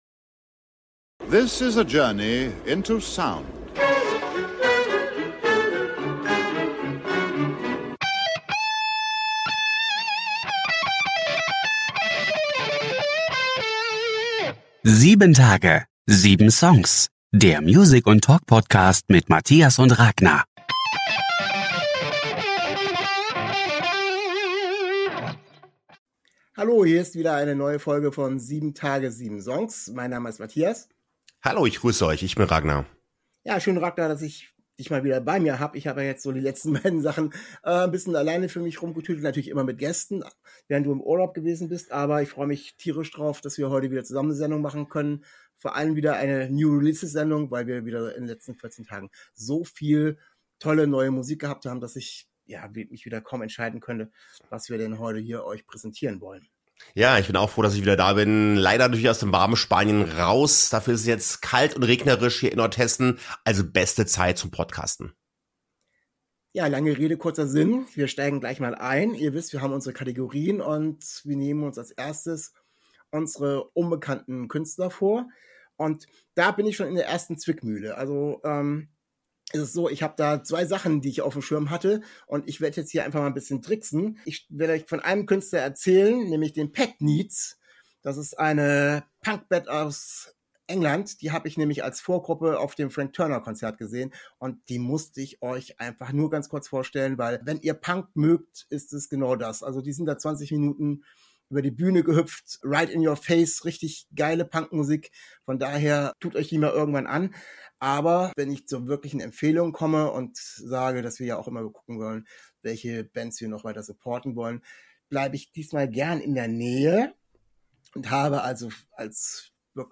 Neue Songs der Woche #38: 7 Tage - 7 Songs: Music + Talk Podcast ~ 7 Tage 7 Songs Podcast